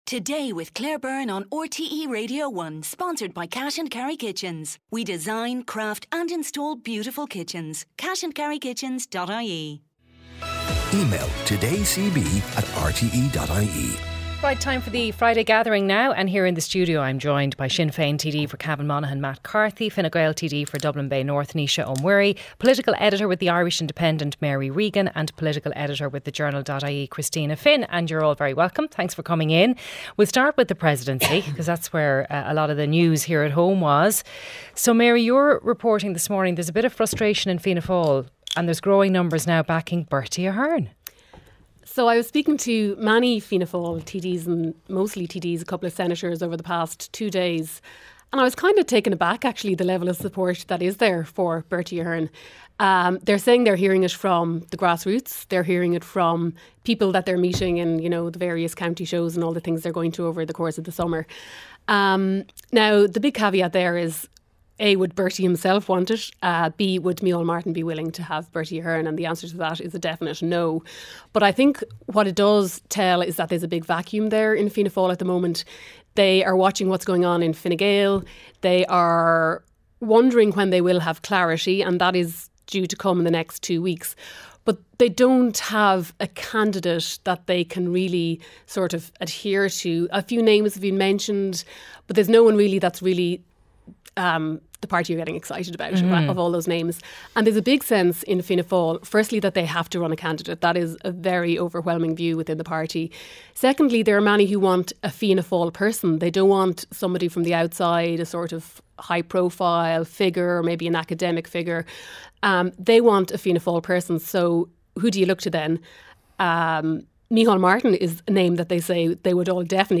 Naoise Ó Muirí Fine Gael TD for Dublin Bay North Matt Carthy, Sinn Fein TD for Cavan Monaghan, Spokesperson on Foreign Affairs and Defence